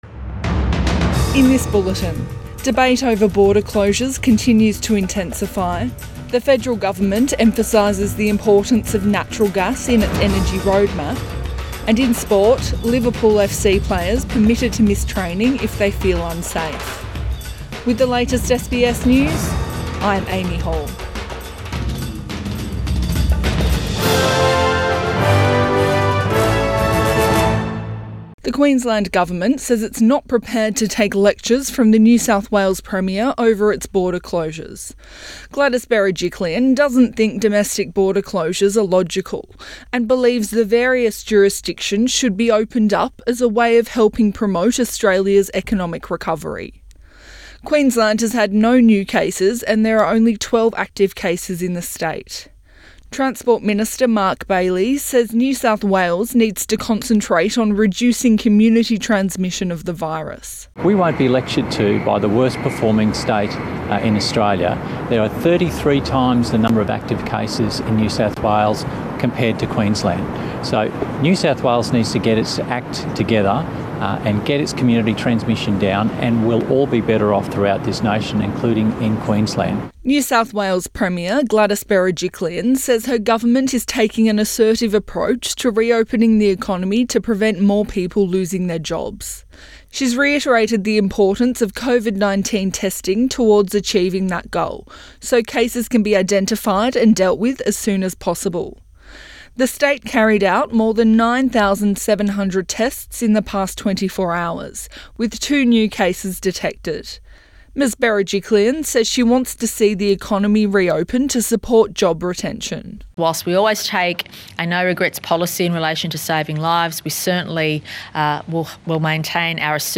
Midday bulletin 21 May 2020